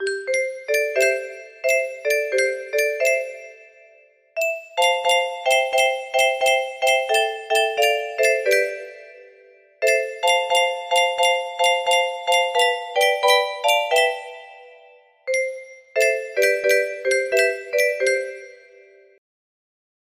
Hotu Matu'a 5 (3 voices) music box melody